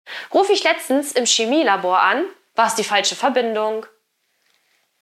Vorgetragen von unseren attraktiven SchauspielerInnen.
Comedy , Unterhaltung , Kunst & Unterhaltung